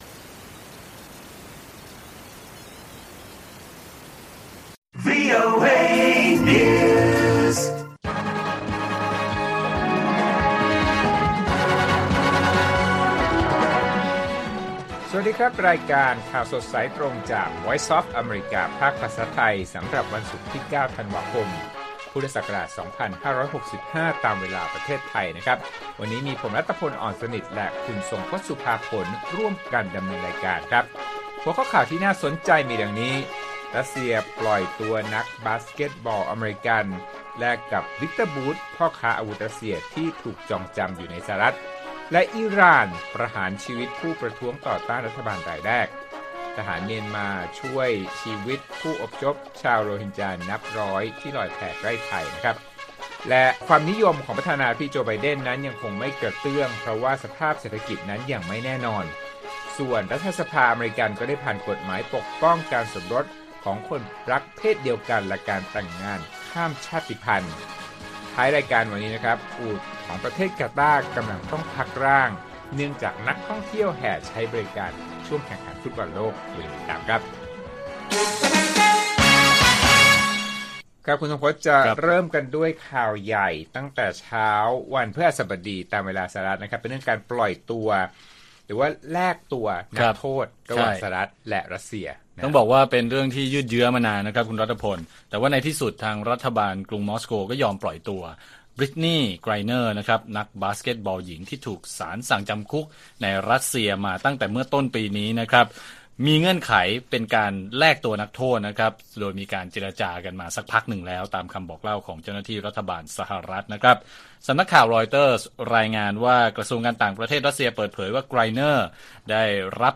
ข่าวสดสายตรงจากวีโอเอไทย 8:30–9:00 น. วันที่ 9 ธ.ค. 65